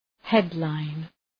{‘hed,laın}
headline.mp3